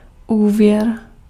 Ääntäminen
US : IPA : [krɛ.dɪt]